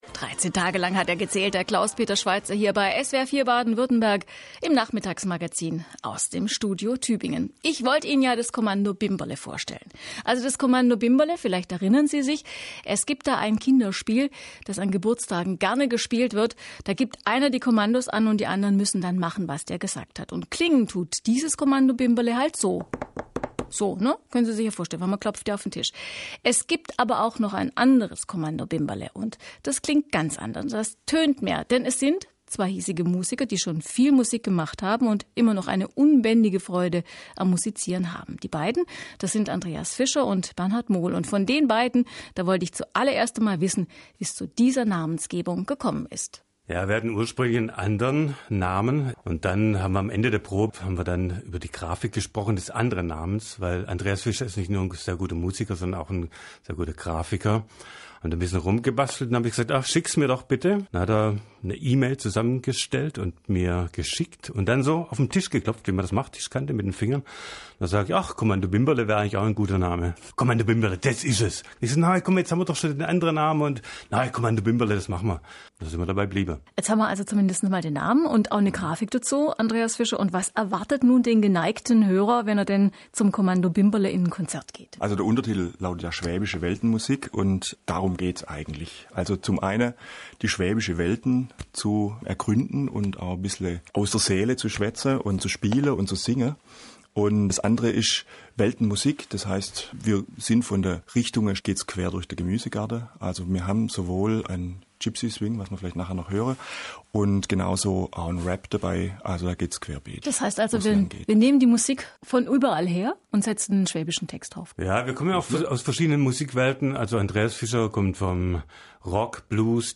Schwäbische Weltenmusik aus Tübingen
Interview
live